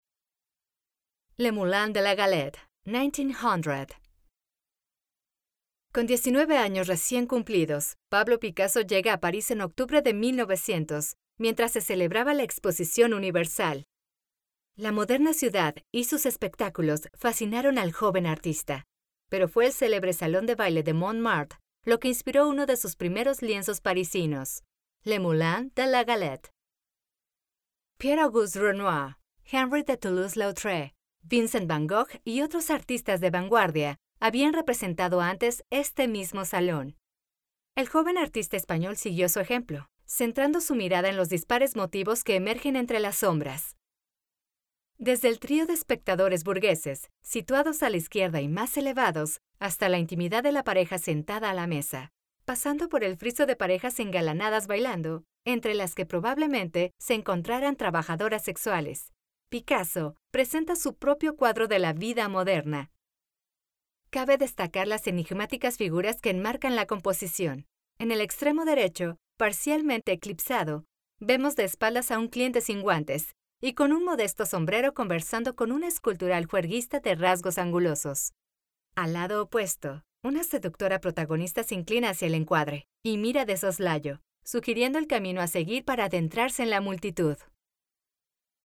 Spaans (Latijns Amerikaans)
Commercieel, Natuurlijk, Stoer, Veelzijdig, Zakelijk
Audiogids